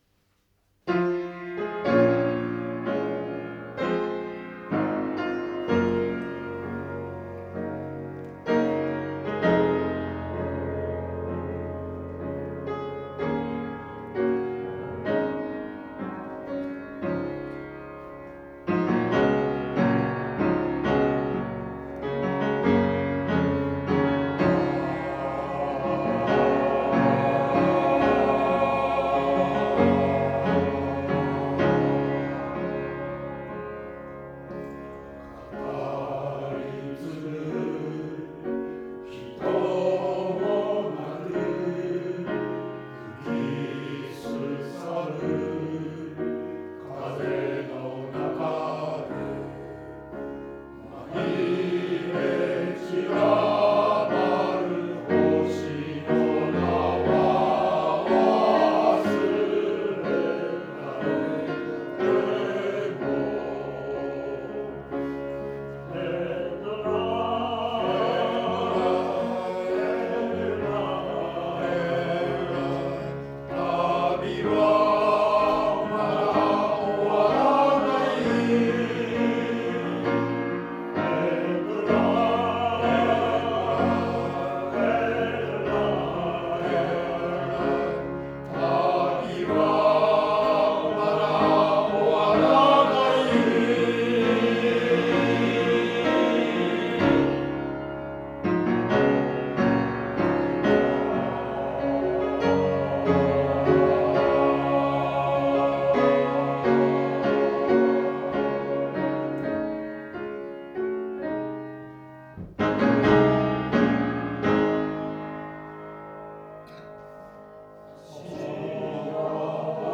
合唱祭が近づく、東小での強化練習
合唱祭が近づき今回は強化練習で、合唱祭で歌う２曲を、時間をかけて練習しました。
四分音符で終わる時のクレッシェンド、２分音符を十分に伸ばして、正確に切る・・・